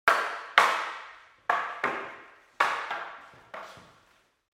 دانلود آهنگ دعوا 13 از افکت صوتی انسان و موجودات زنده
جلوه های صوتی
دانلود صدای دعوای 13 از ساعد نیوز با لینک مستقیم و کیفیت بالا